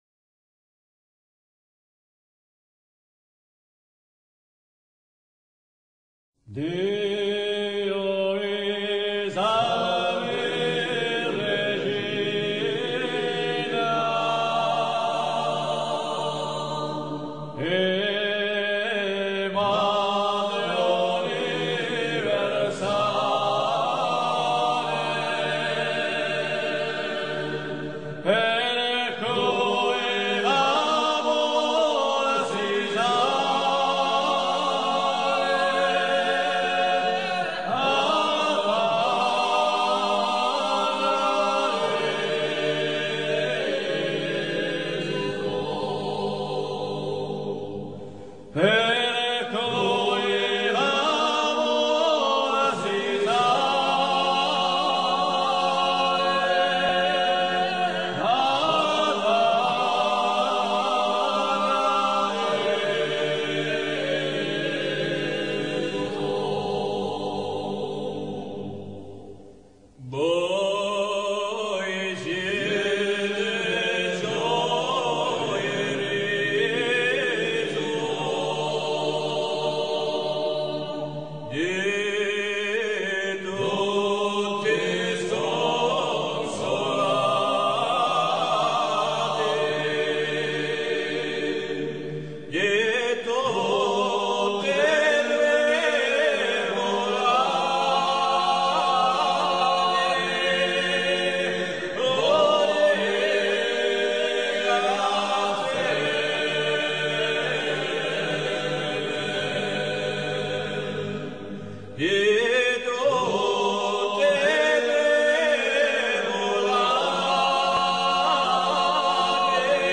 Ci-dessous, écoute un chant polyphonique corse : Dio Vi Salvi Regina